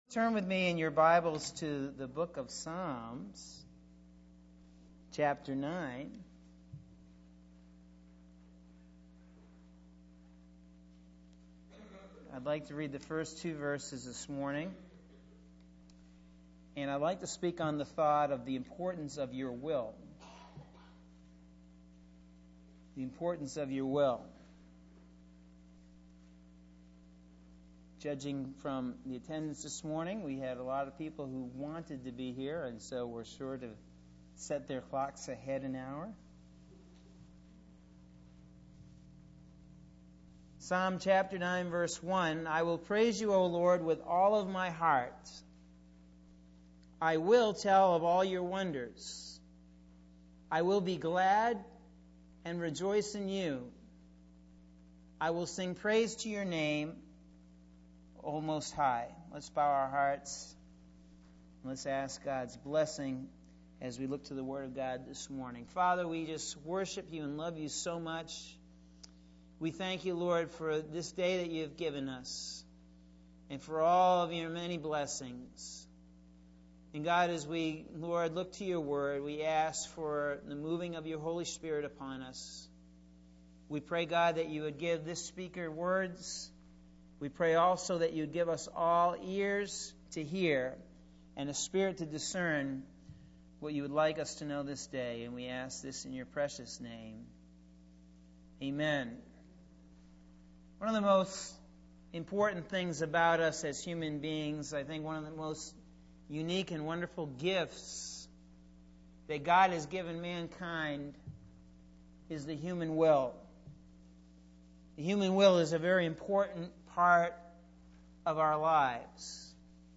Sunday March 14th – AM Sermon – Norwich Assembly of God